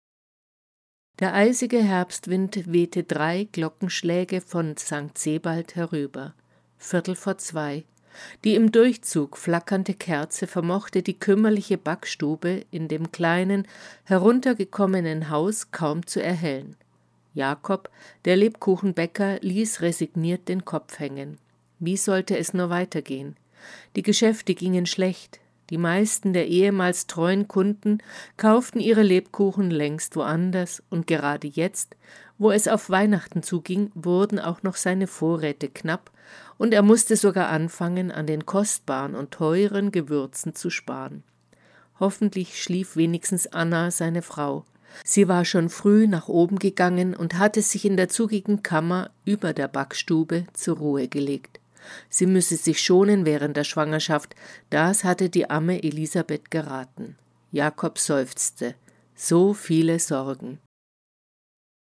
DAISY-Hörbuch